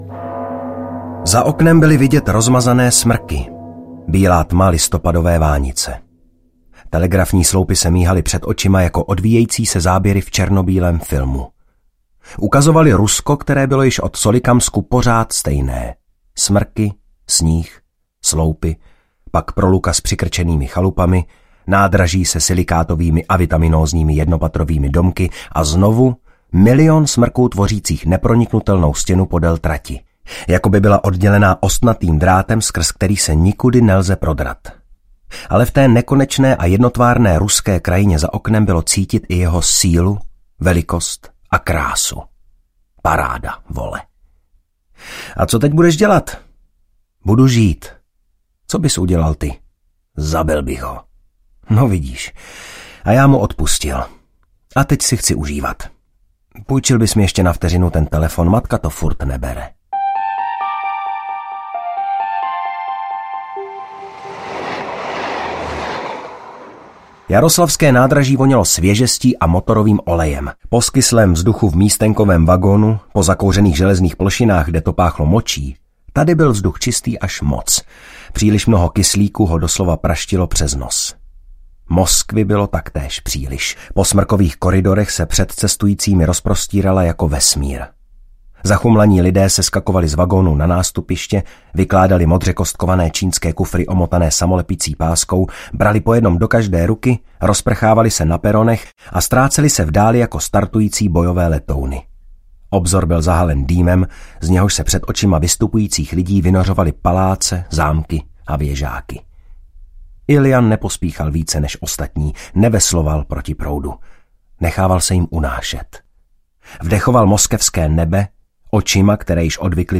Text audiokniha
Ukázka z knihy